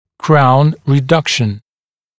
[kraun rɪ’dʌkʃn][краун ри’дакшн]уменьшение высоты коронки (напр. при интрузии)